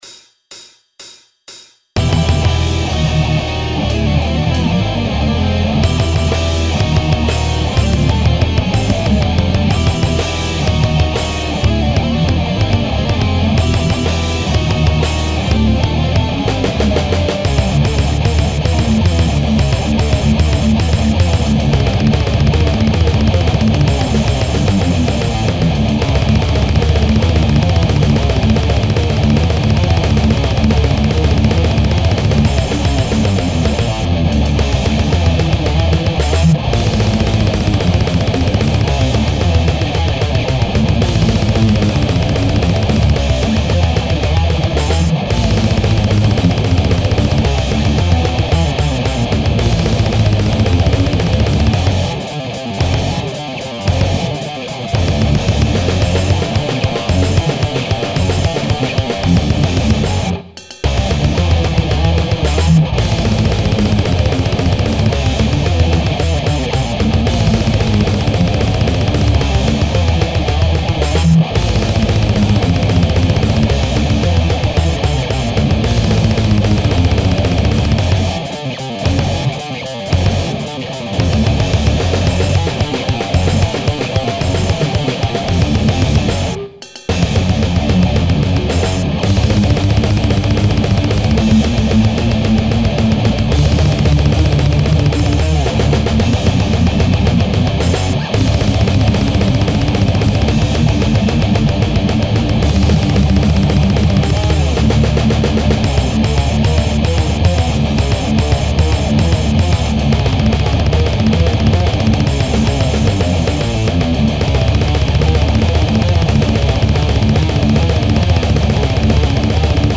Blast (An All Out Thrash Assault)